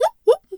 pgs/Assets/Audio/Animal_Impersonations/zebra_whinny_10.wav at master
zebra_whinny_10.wav